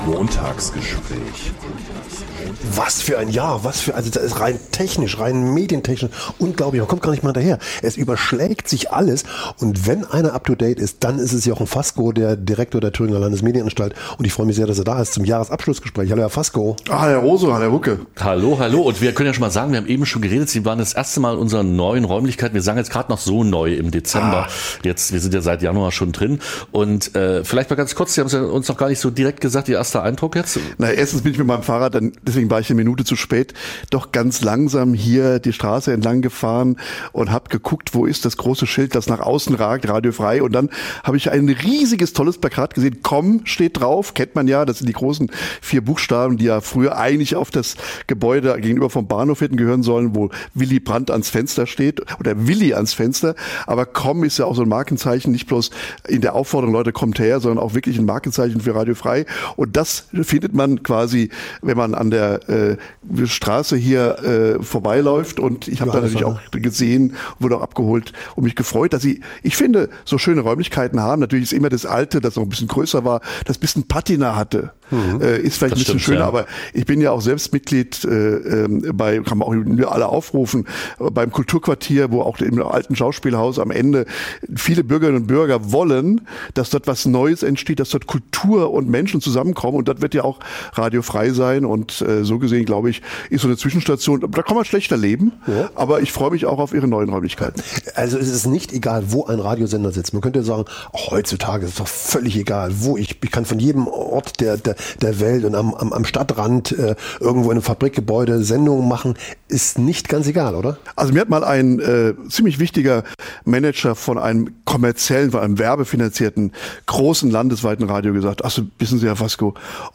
Das Gespr�ch
Jochen Fasco | Direktor der Thüringer Landesmedienanstalt (TLM)